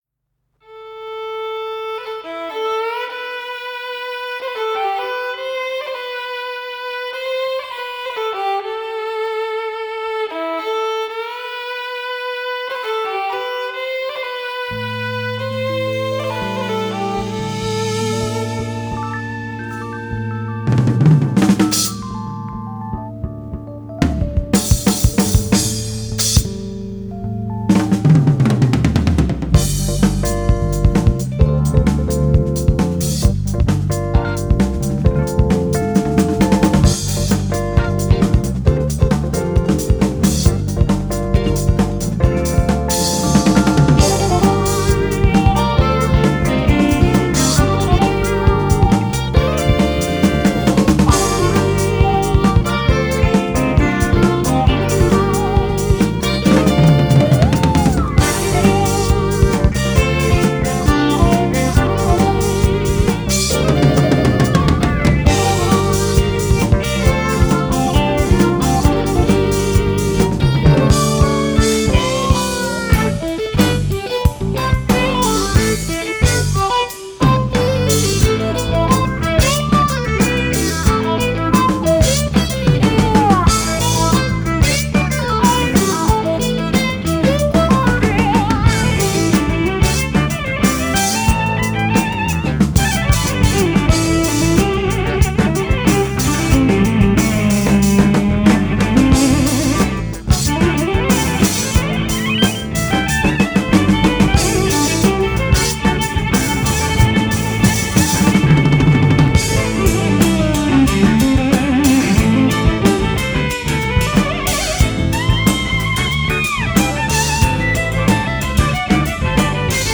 Celtic fusion violinist